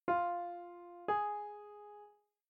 Minor third
Increasing a pitch by one fifth (a ratio of 6:5) creates an interval which we call a minor third.
minor3.mp3